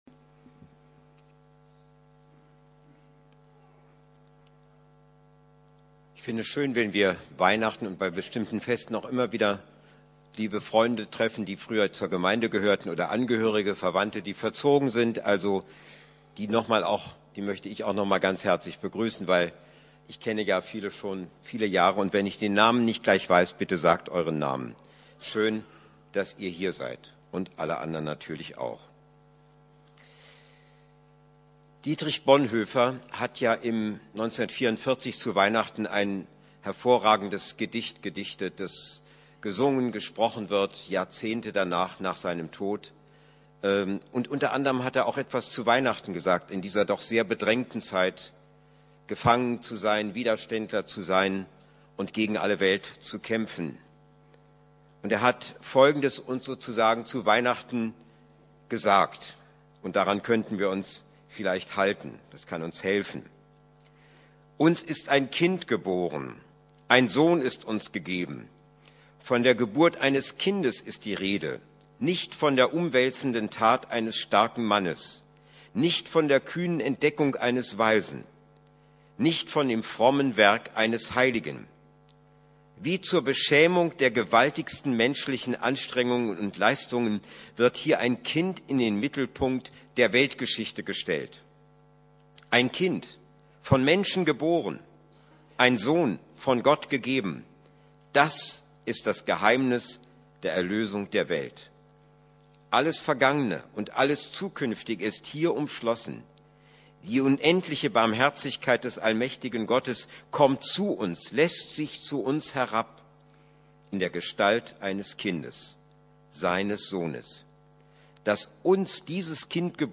Weihnachtsgottesdienst